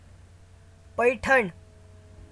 Paithan (['pəɪ.ʈʰəɳ]
Paithan.ogg.mp3